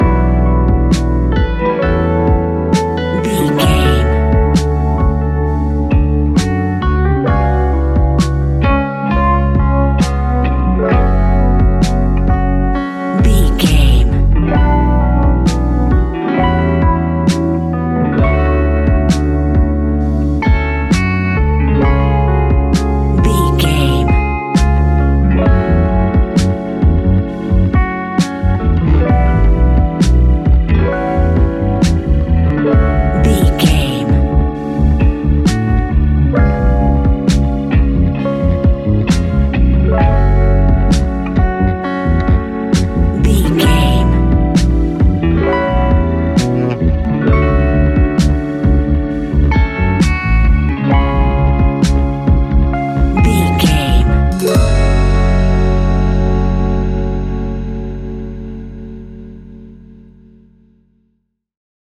Ionian/Major
F♯
laid back
sparse
chilled electronica
ambient
atmospheric